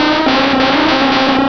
cry_exeggutor.aif